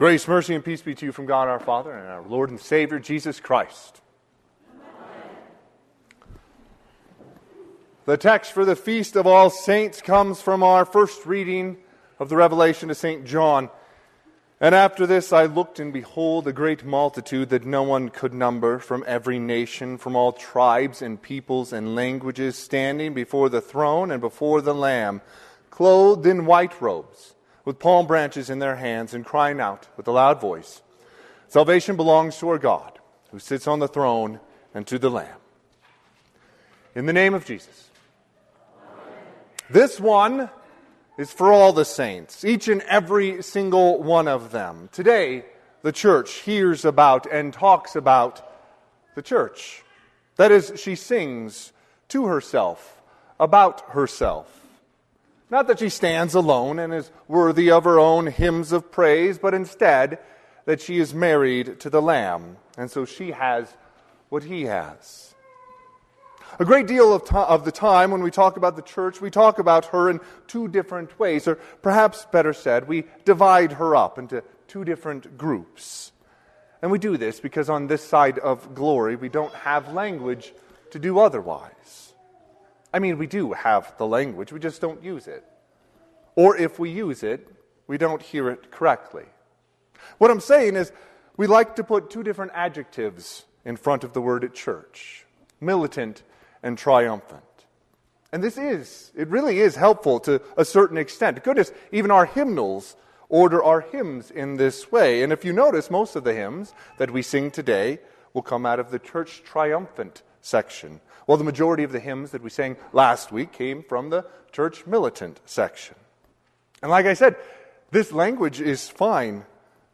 Sermon - 11/3/2024 - Wheat Ridge Lutheran Church, Wheat Ridge, Colorado
Sermon_Nov3_2024.mp3